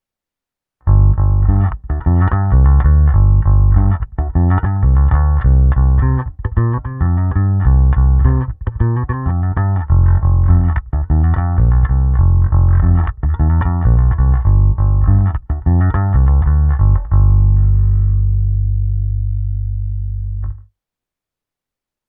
Ne tak zvonivý, ale zato parádně pevný, tučný, a to i s přihlédnutím k tomu, že jsem basu dostal s hlazenkami s nízkým tahem.
Simulace boxu Science 2×15"